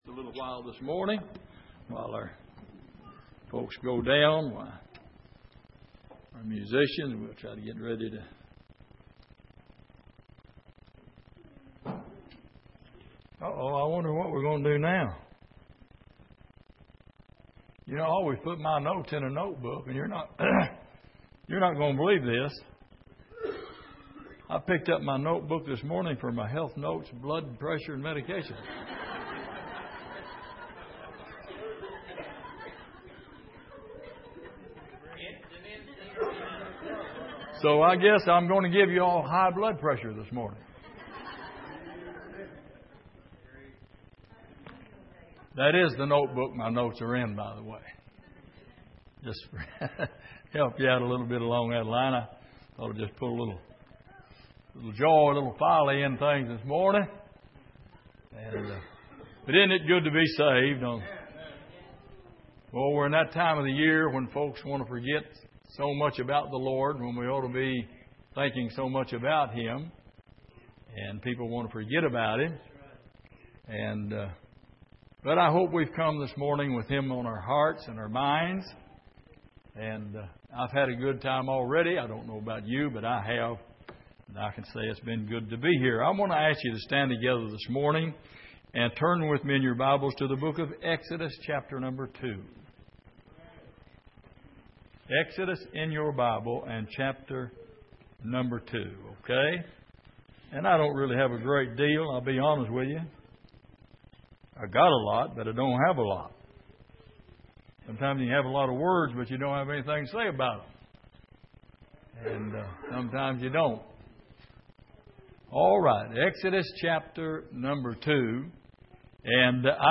Passage: Exodus 2:1-10 Service: Sunday Morning